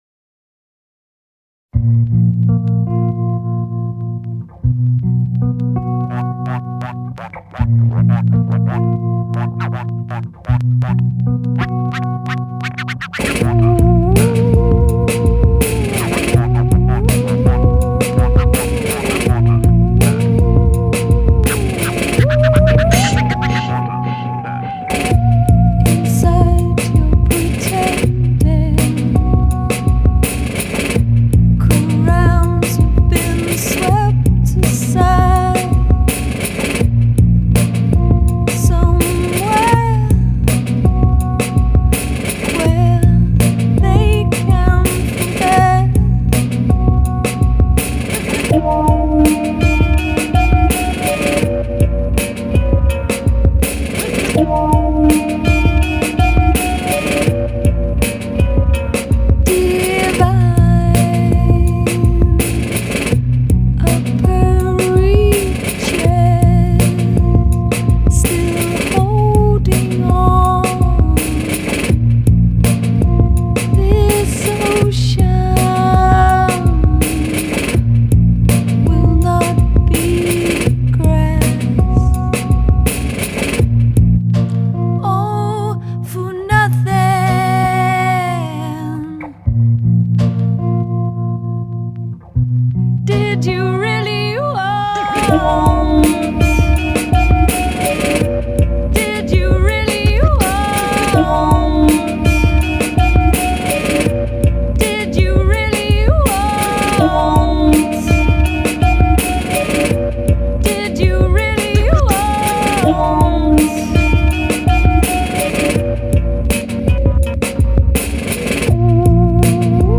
Bristol, angustia, trip hop.